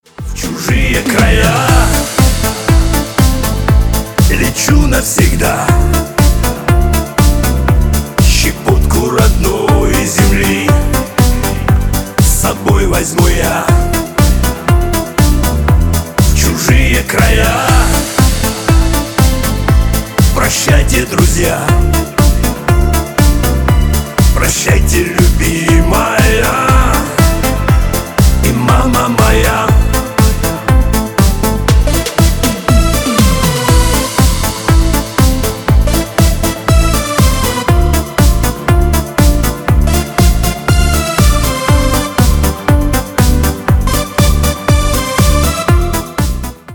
• Категория: Рингтоны